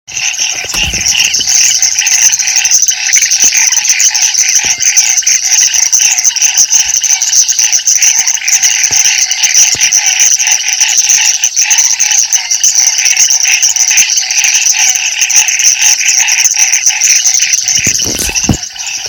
Rana Trepadora Chaqueña (Boana raniceps)
Nombre en inglés: Chaco-Tree Frog
Provincia / Departamento: Corrientes
Condición: Silvestre
Certeza: Vocalización Grabada